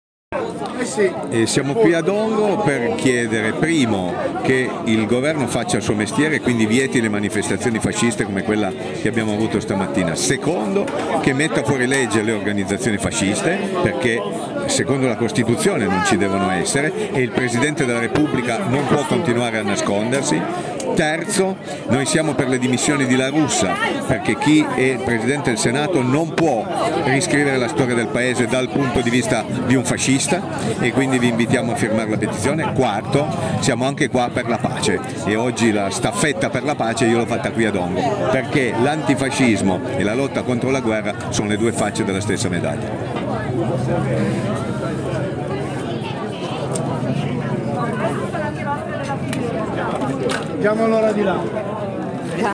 Dichiarazioni alla fine della manifestazione
Paolo Ferrero, direzione Prc.